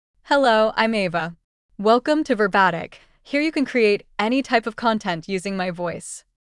AvaFemale English AI voice
Ava is a female AI voice for English (United States).
Voice: AvaGender: FemaleLanguage: English (United States)ID: ava-en-us
Voice sample
Listen to Ava's female English voice.
Ava delivers clear pronunciation with authentic United States English intonation, making your content sound professionally produced.